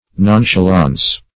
Nonchalance \Non`cha`lance"\, n. [F. See Nonchalant.]